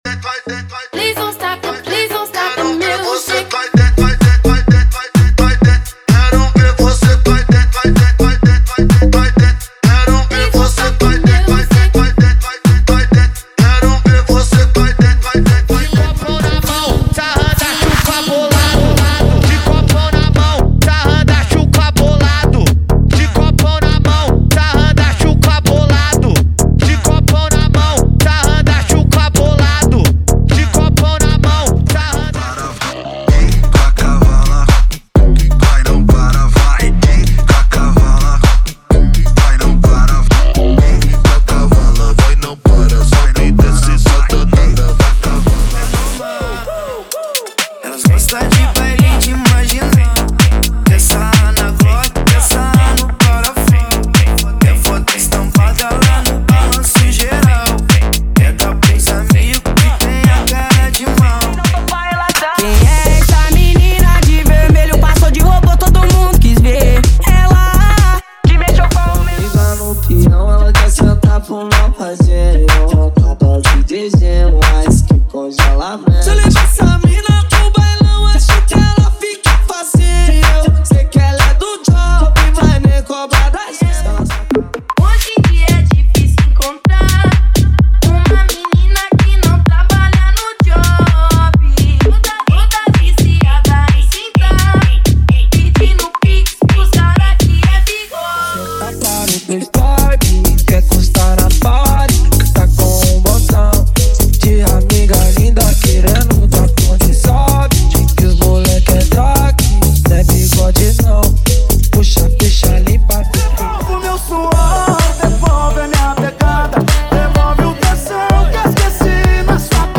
• Mega Funk, Mega Minimal e Mega Nejo = 50 Músicas
• Sem Vinhetas
• Em Alta Qualidade